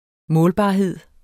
Udtale [ ˈmɔːlbɑˌheðˀ ]